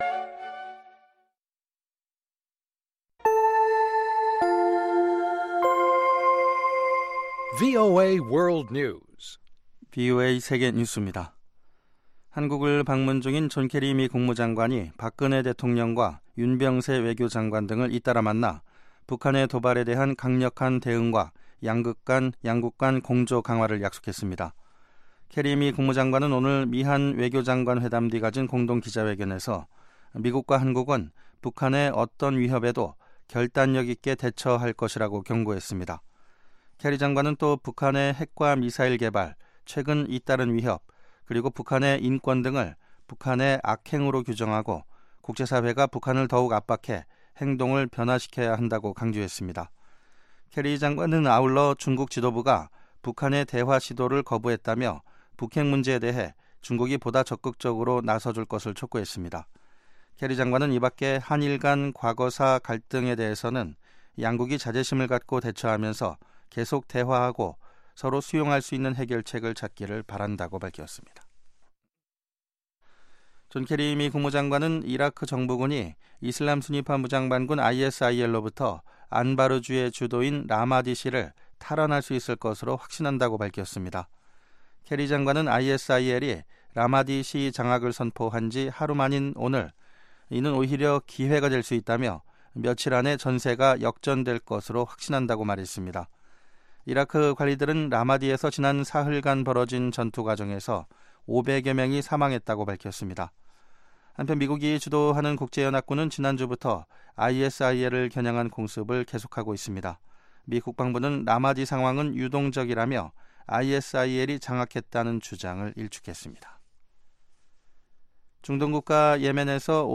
VOA 한국어 방송의 간판 뉴스 프로그램 '뉴스 투데이' 2부입니다. 한반도 시간 매일 오후 9시부터 10시까지 방송됩니다.